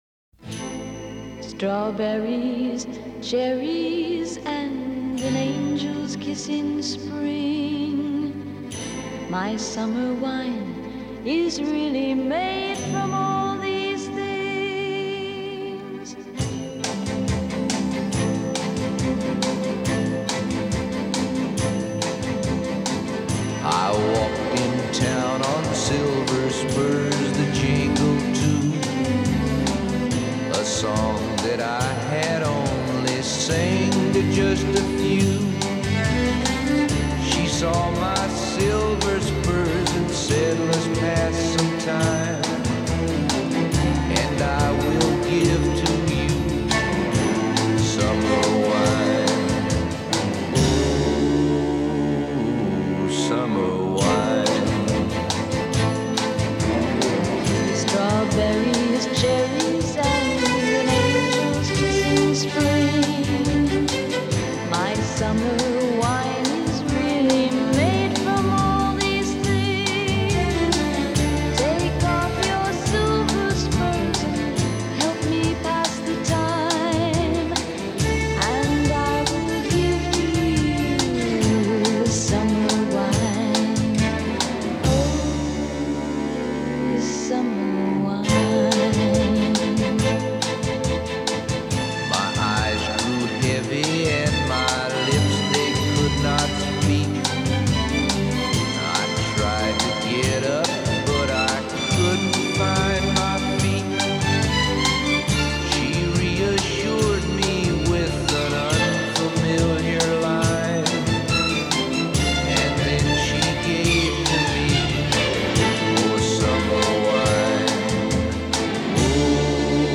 There’s the innocent female vocal, parping brass